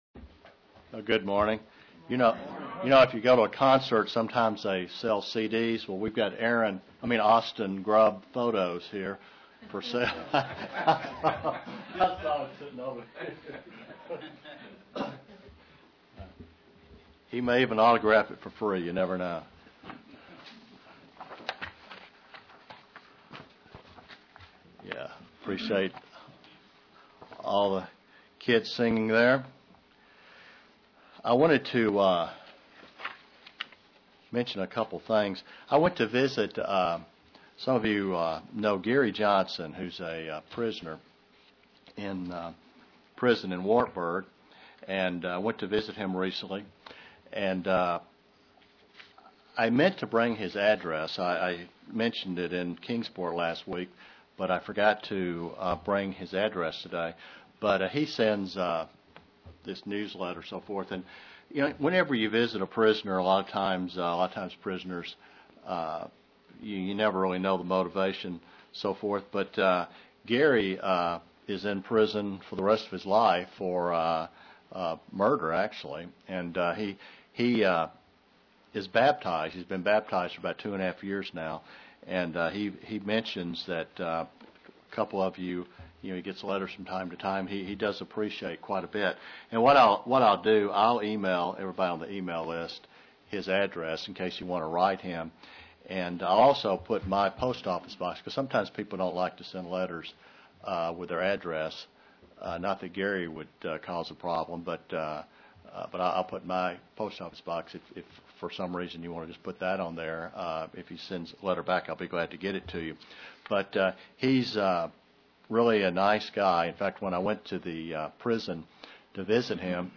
Print How to properly study prophecy in the Bible UCG Sermon Studying the bible?
Given in London, KY